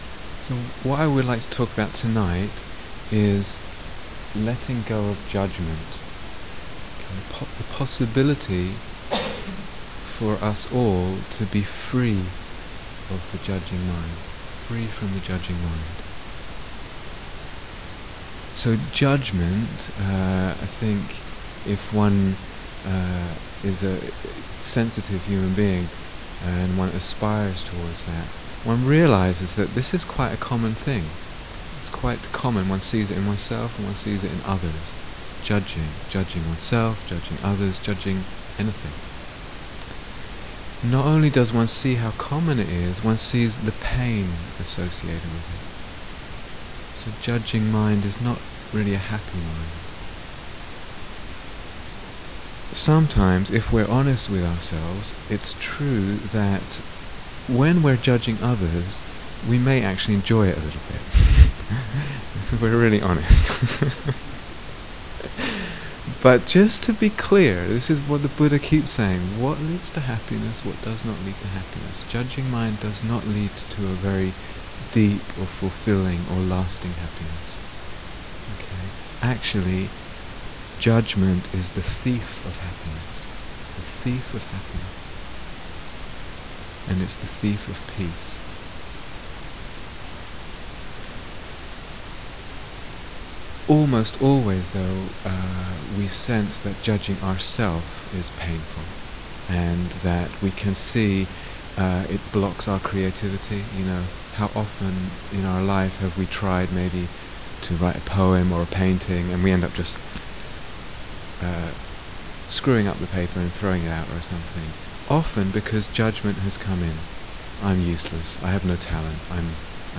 Silent Autumn Retreat, Finland 2006